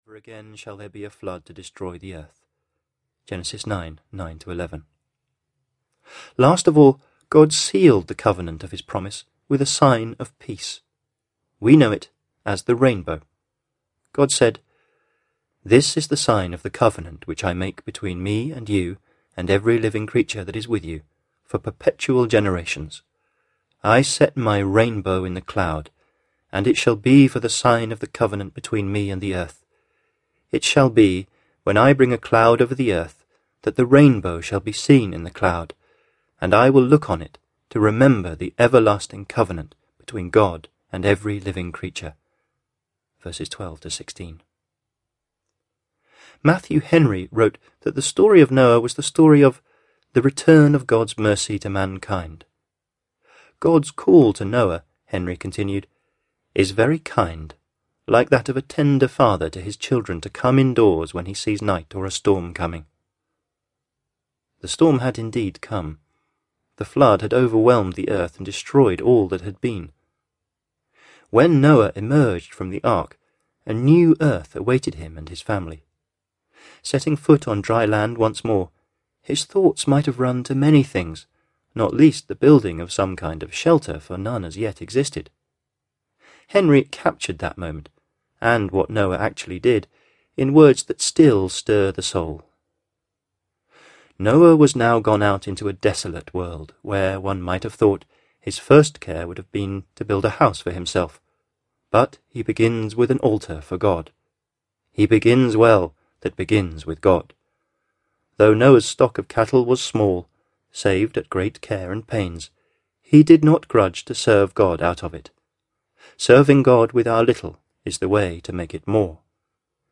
Miraculous Audiobook